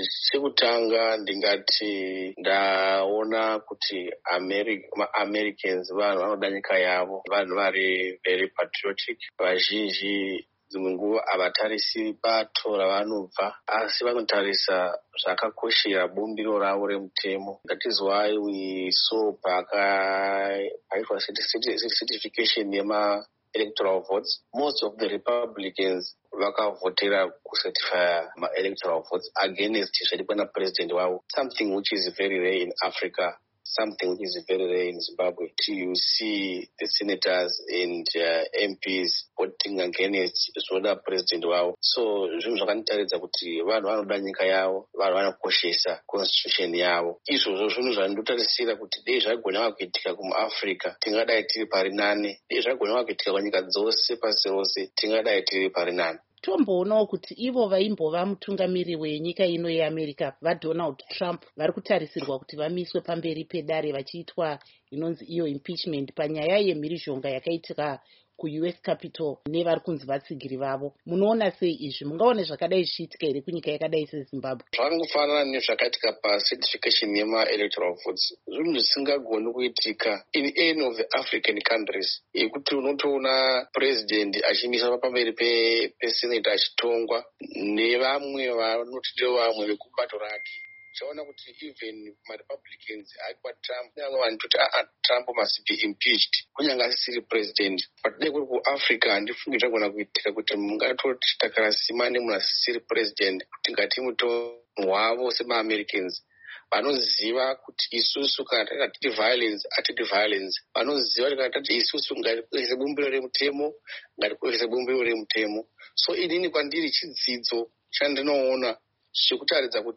Hurukuro naVaGodfrey Kurauone